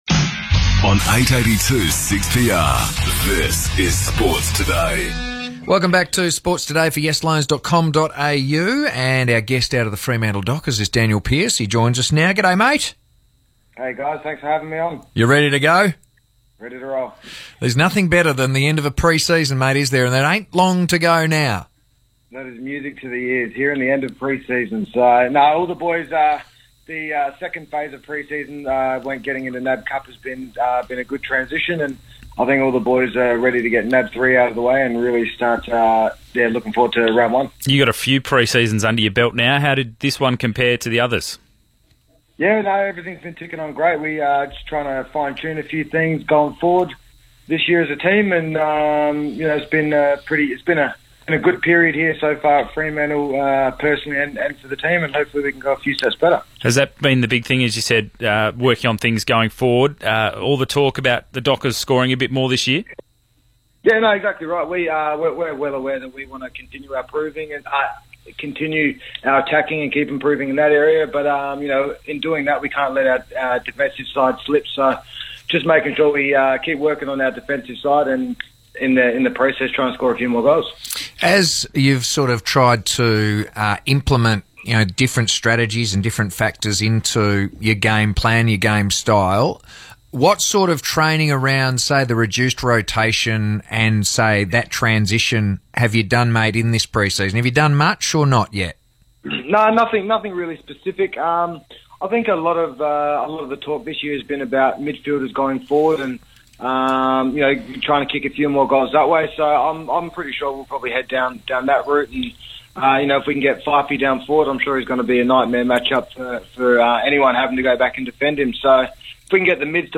Danyle Pearce spoke to the boys prior to the NAB 3 match against Geelong